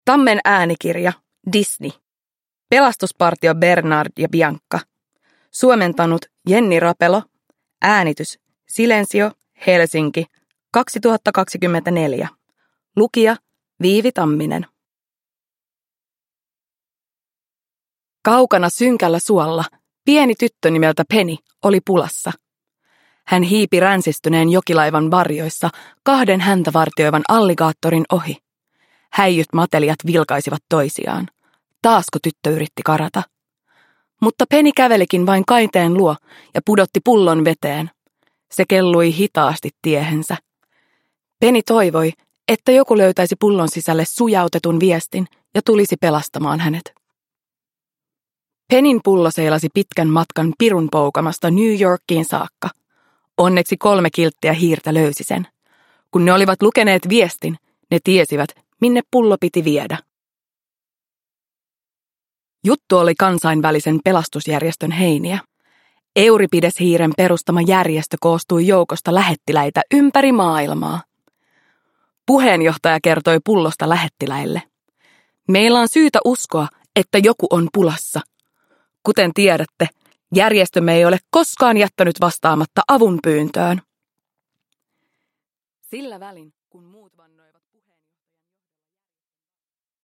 Disney. Pelastuspartio Bernard ja Bianca. Satuklassikot – Ljudbok